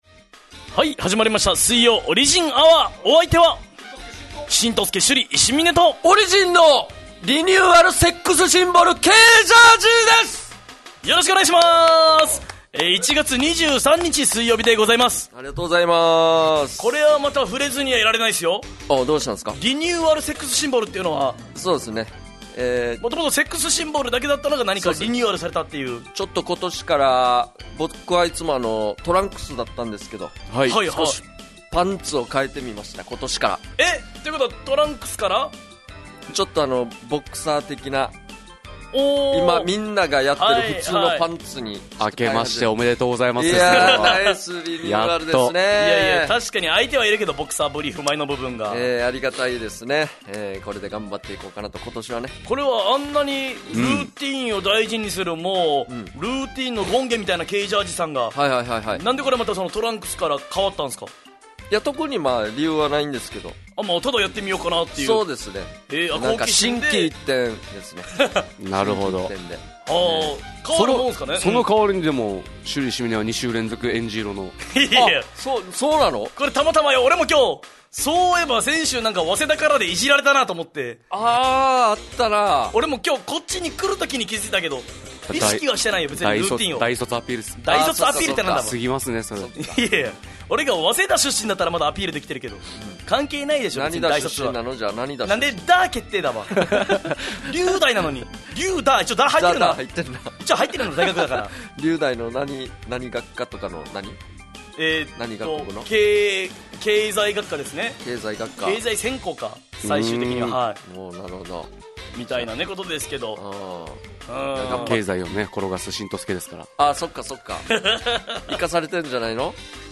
沖縄のFMラジオ局 fm那覇。